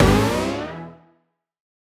Index of /musicradar/future-rave-samples/Poly Chord Hits/Ramp Up